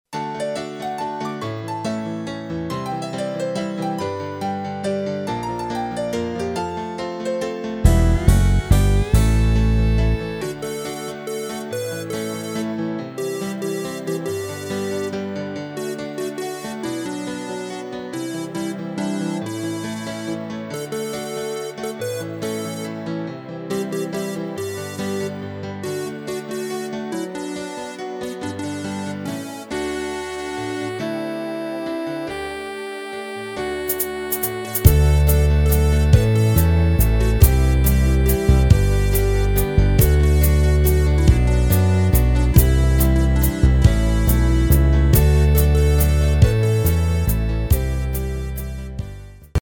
Rubrika: Folk, Country